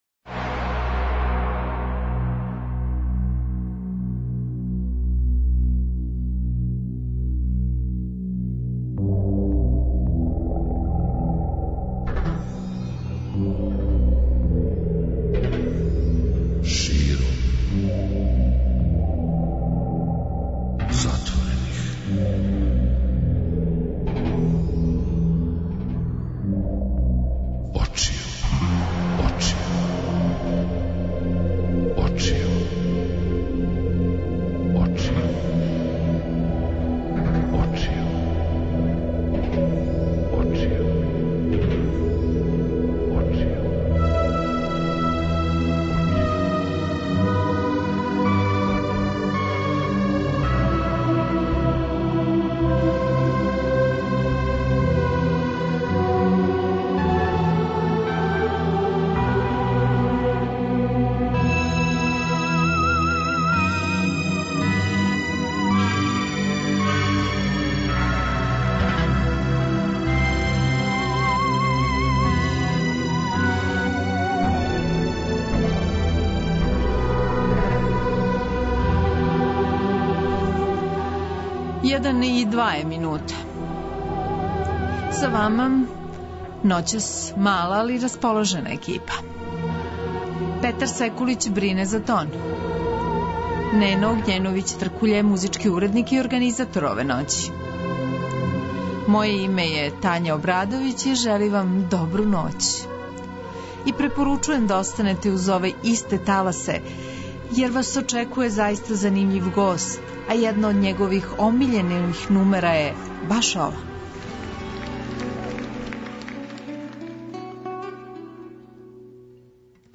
Гост емисије: Срђан Марјановић.